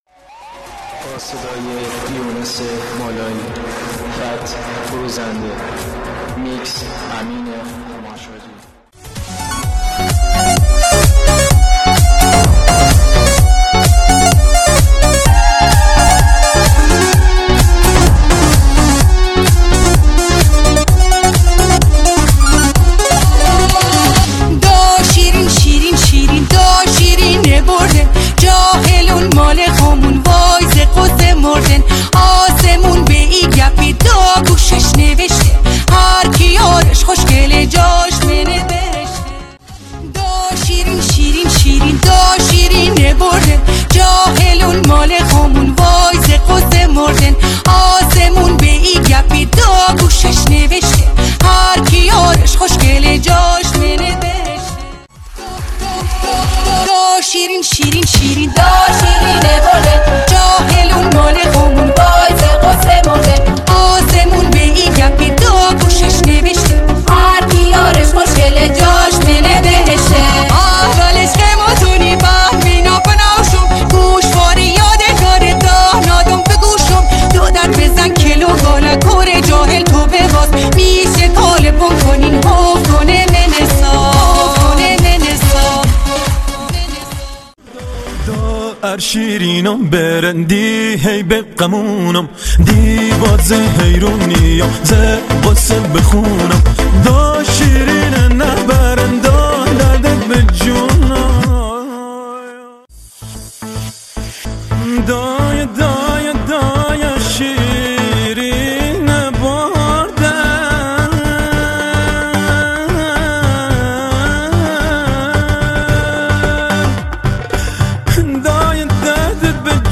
آهنگ شاد لری
میکس آهنگ بختیاری شاد